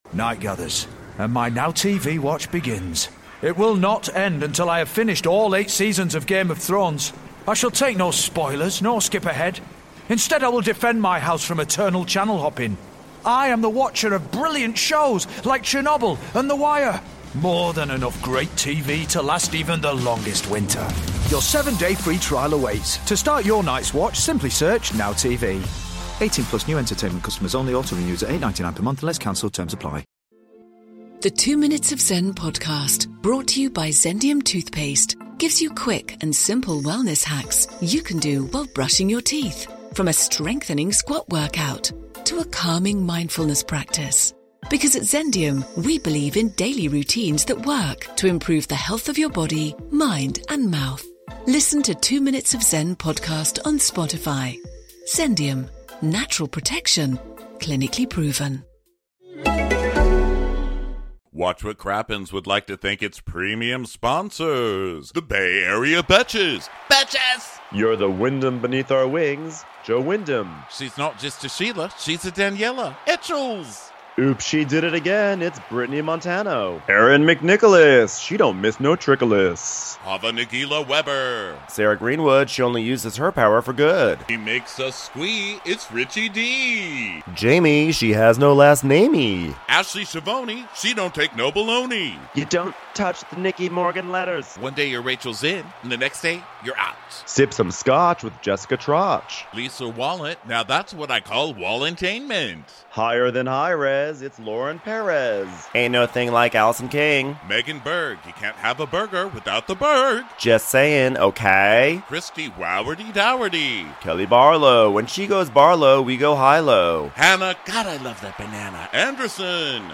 This week's Real Housewives of New Jersey was recorded live from Warehouse Live in Houston, TX. The fight about childrens' party budgets rages, Tre and Joe Gorga get steamy over cauliflower, and our live audience reaches levels of coo-coo never before heard in the history of live shows.